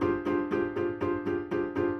Index of /musicradar/gangster-sting-samples/120bpm Loops
GS_Piano_120-G2.wav